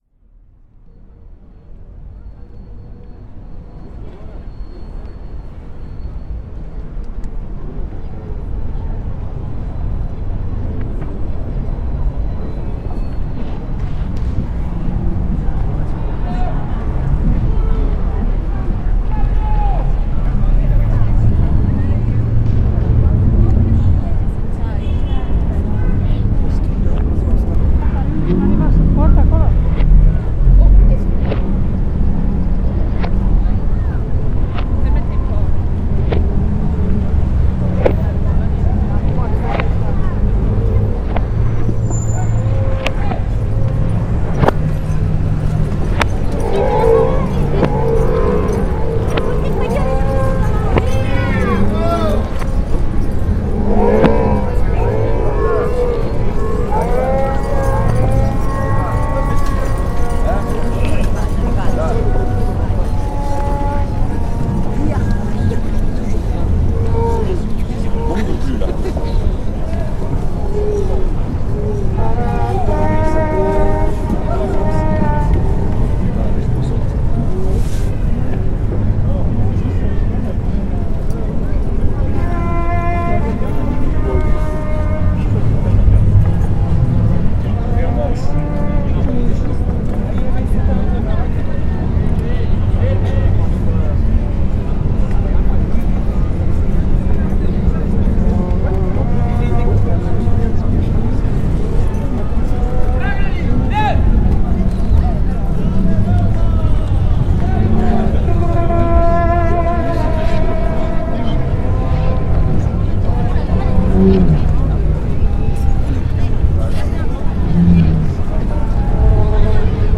Roman reenactment reimagined